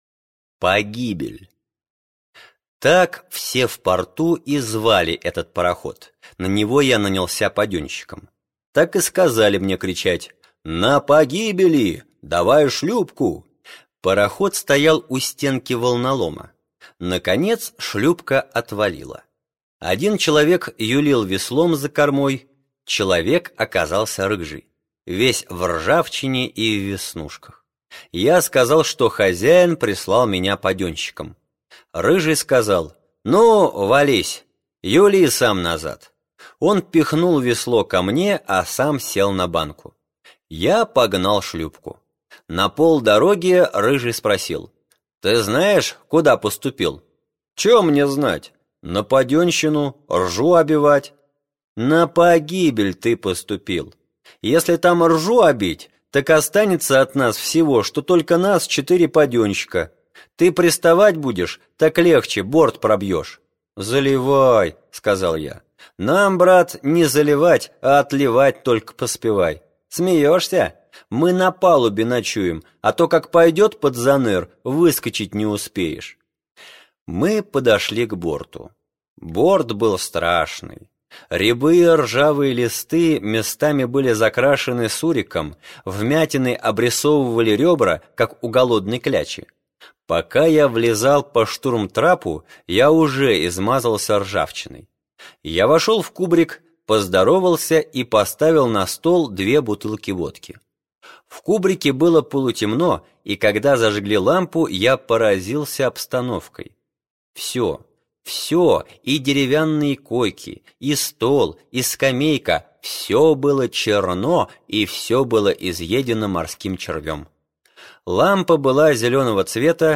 Погибель - аудио рассказ Житкова - слушать скачать